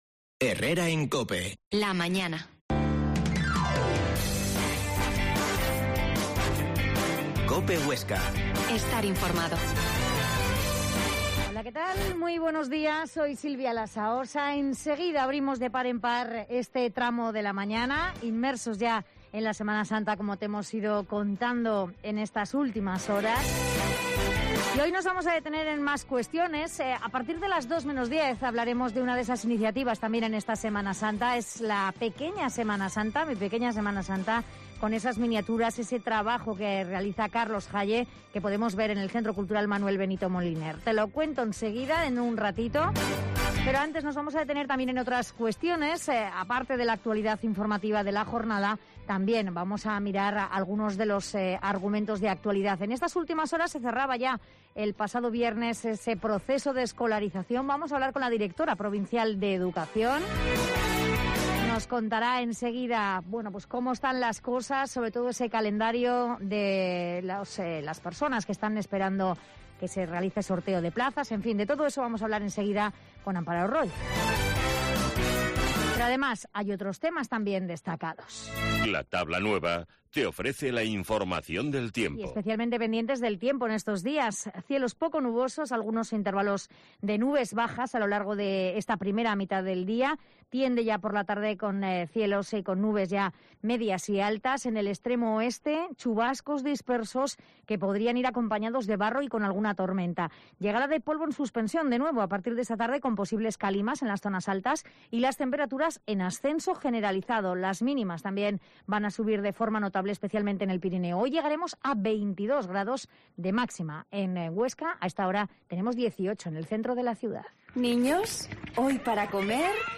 Herrera en COPE Huesca 12.50h Entrevista a la Directora provincial de educación Amparo Roig